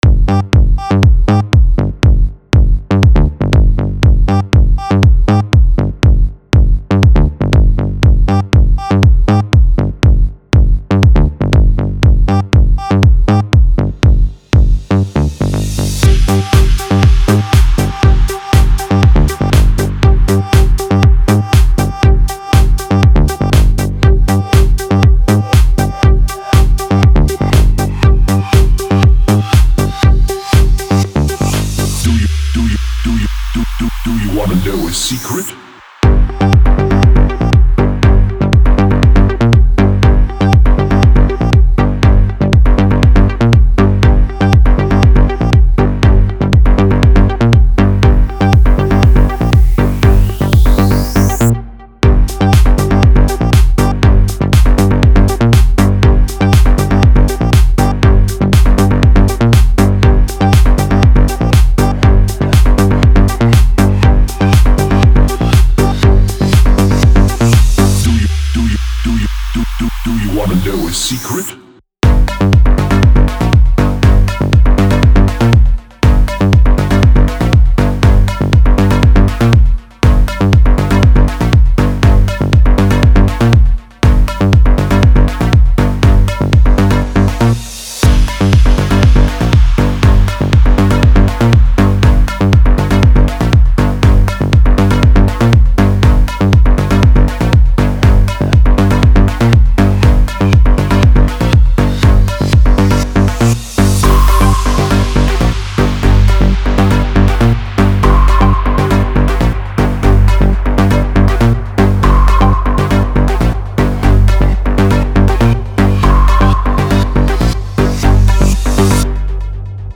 Single DJ Remix Songs